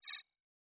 Holographic UI Sounds 49.wav